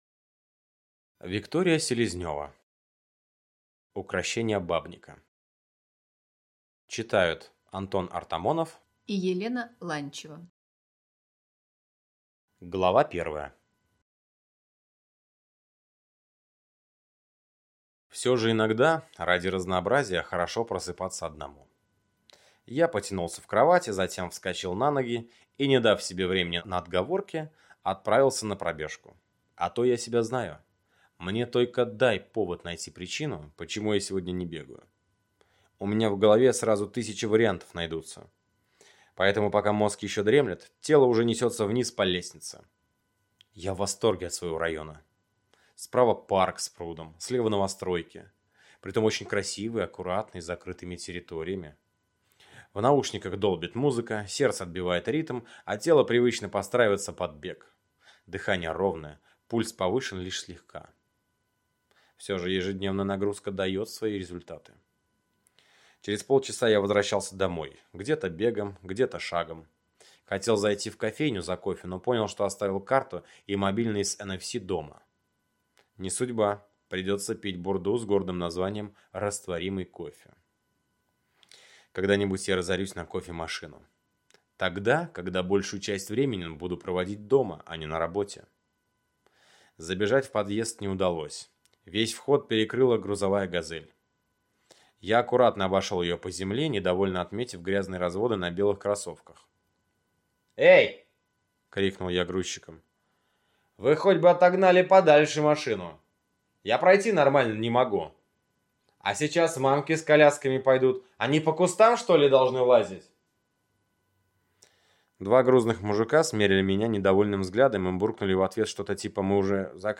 Аудиокнига Укрощение бабника | Библиотека аудиокниг